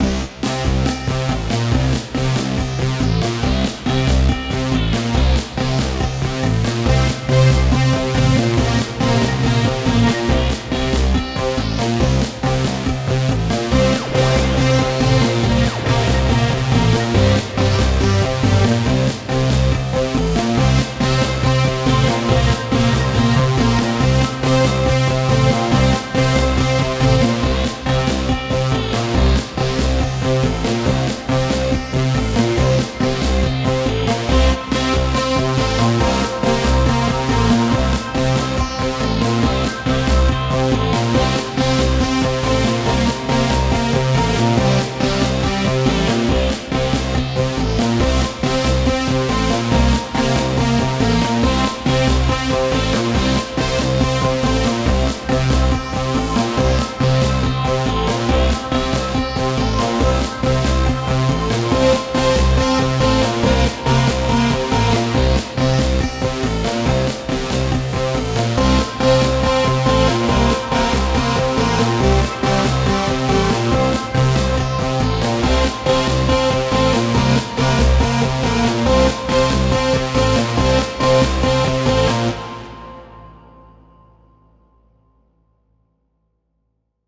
A techy track